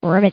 FROG.mp3